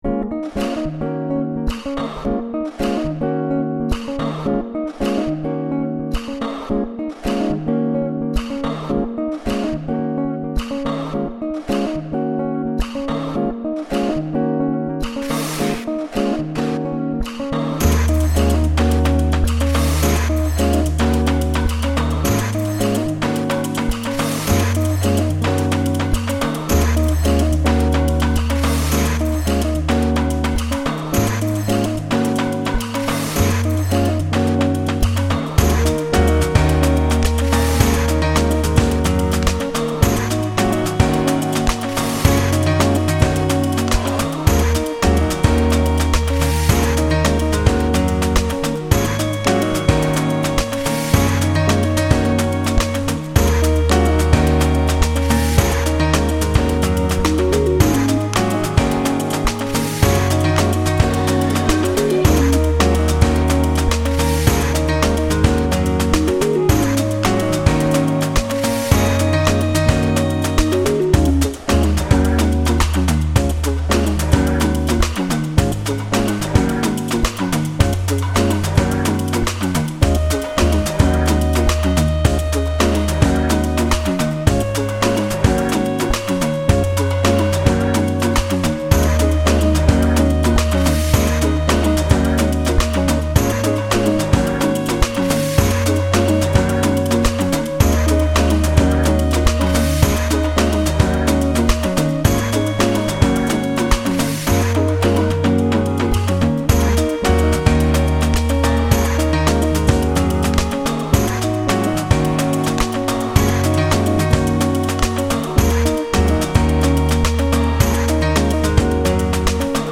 Sao Paulo cafe reimagined